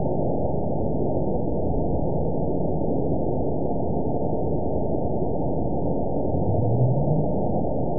event 920966 date 04/20/24 time 21:43:42 GMT (1 year, 2 months ago) score 9.56 location TSS-AB02 detected by nrw target species NRW annotations +NRW Spectrogram: Frequency (kHz) vs. Time (s) audio not available .wav